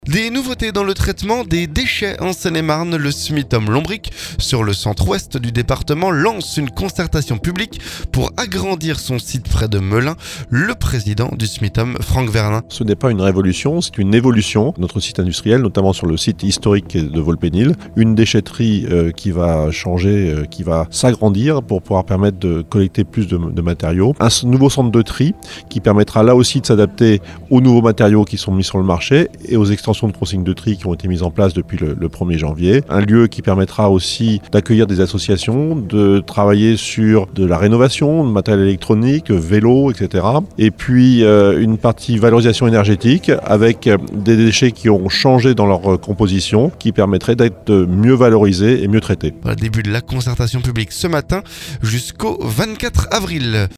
Des nouveautés dans le traitement des déchets en Seine-et-Marne. Le Smitom-Lombric, sur le centre Ouest du département, lance une concertation publique pour agrandir son site près de Melun. Le présidet du Smitom Franck Vernin.